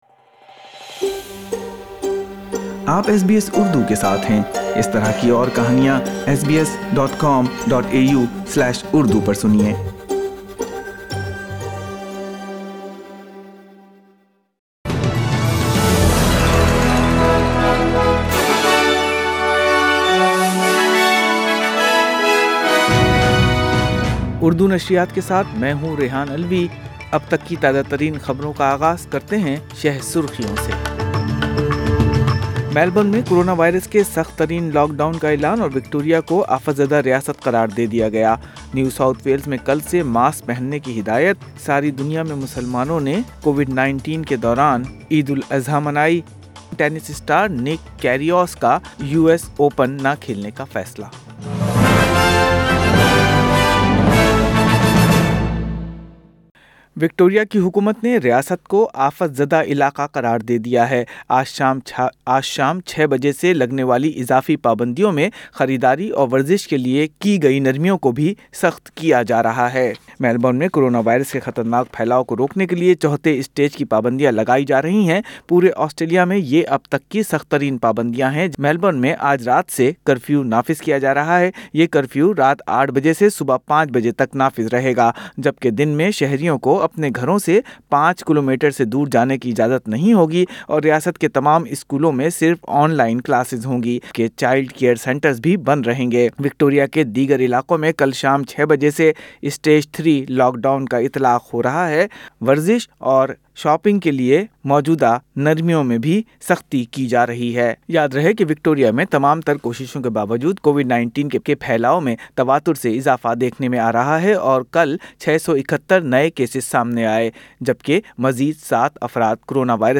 اردو خبریں 2 اگست 2020